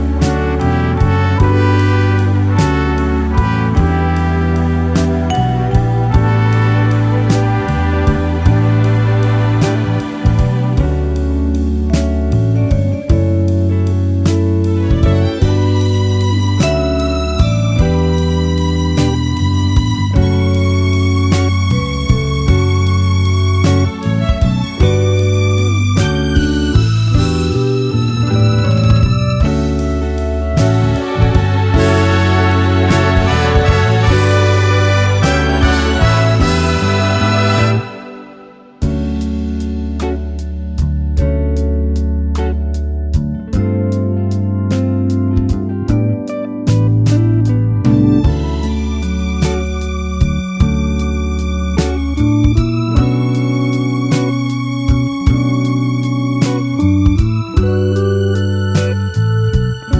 no Backing Vocals Disco 3:43 Buy £1.50